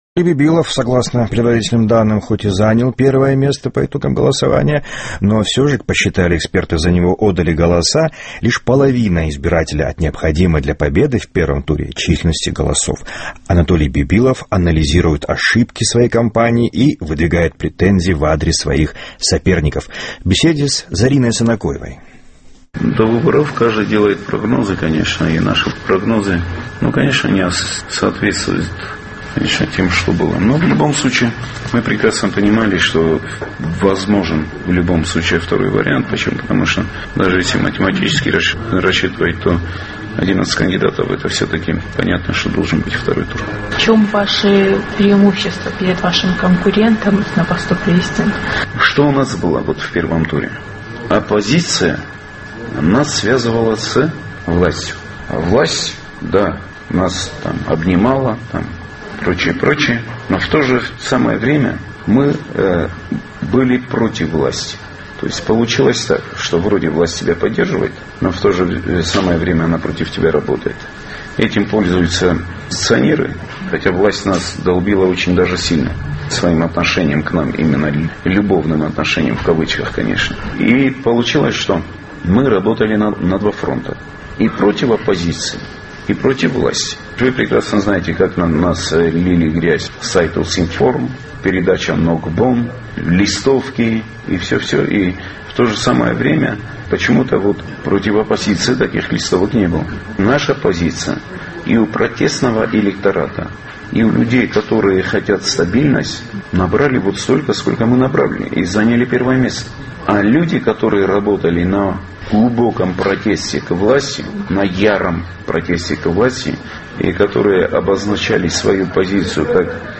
ЦХИНВАЛИ---Анатолий Бибилов, хотя и занял первое место по итогам первого тура голосования, но набрал лишь половину голосов, необходимых для победы. Он анализирует ошибки своей избирательной кампании и выдвигает претензии в адрес своих конкурентов на выборах в беседе с нашим корреспондентом